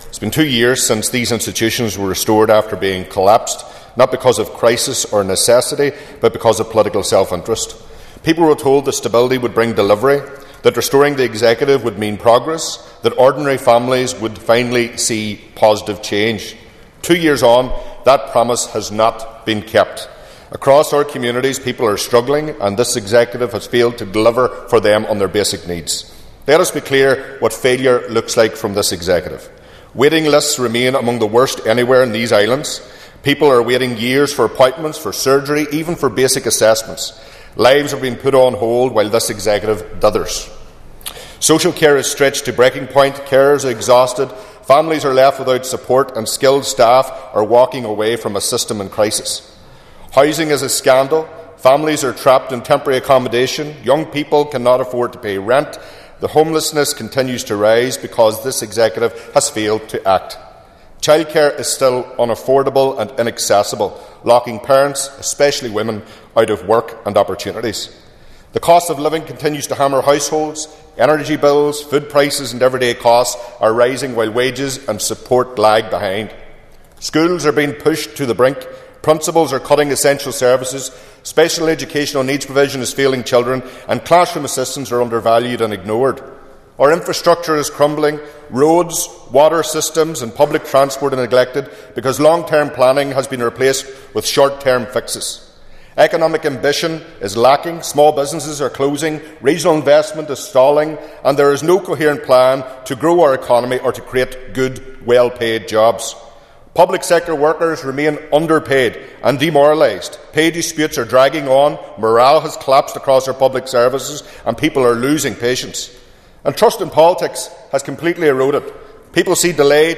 West Tyrone MLA Daniel McCrossan says there is no coherent plan coming from the Executive which continues to hide behind excuses.
In a speech marking the second anniversary this week, he told the Assembly people want delivery, but they are losing patience………..